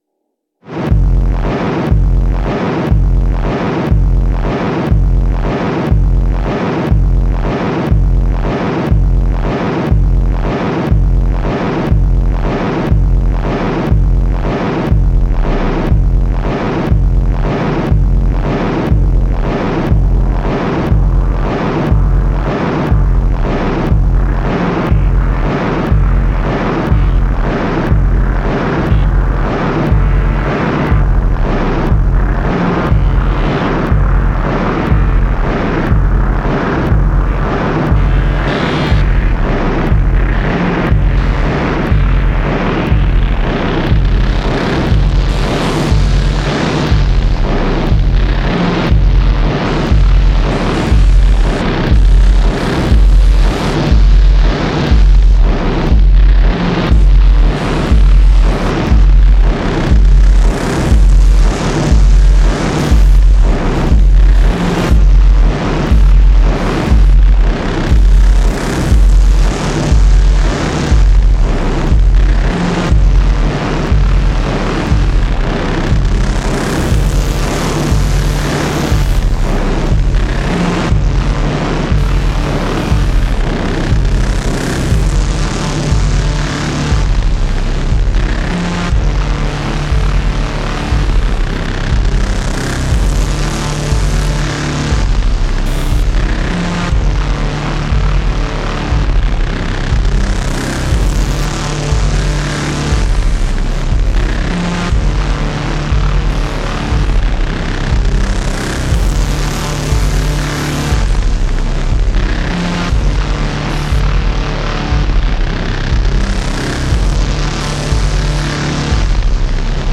Octatrack! Samples were processed with Oto Boum beforehand to get some extra crunch. Synth and piano part played on Nord Wave.